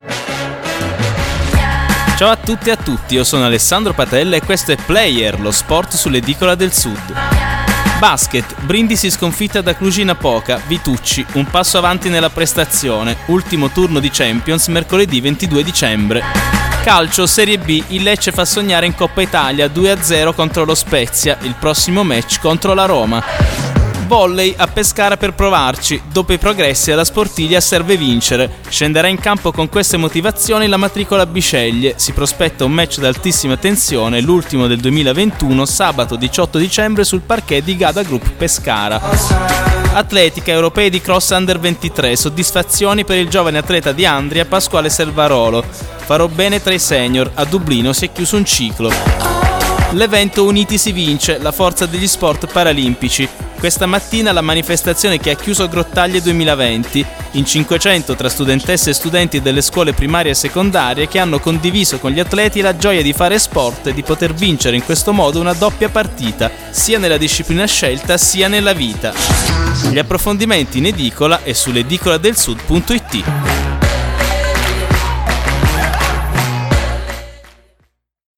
Giornale radio sportivo